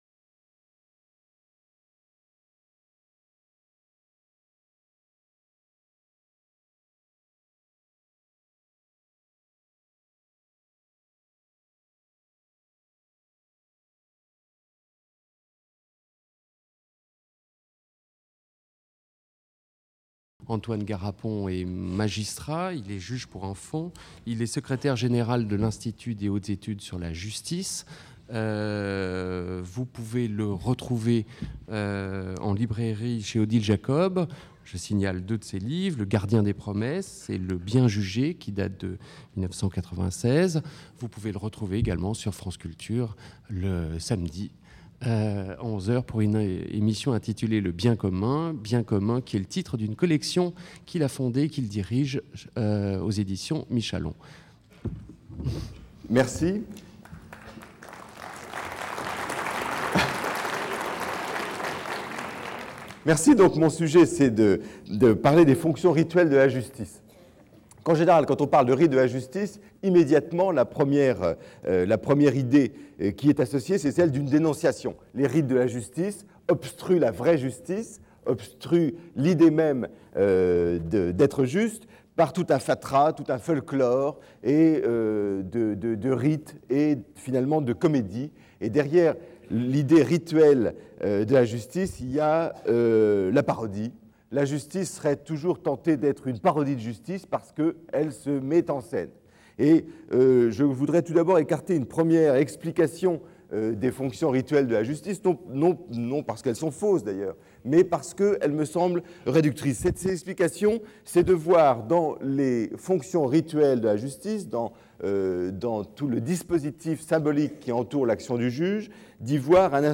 Conférence du 20 février 2000 par Antoine Garapon. Les formes rituelles de la justice ont-elles un avenir ?